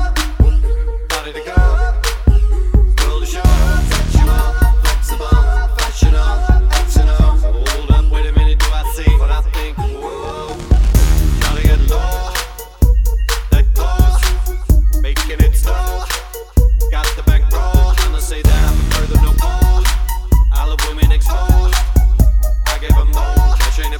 no Backing Vocals R'n'B / Hip Hop 3:49 Buy £1.50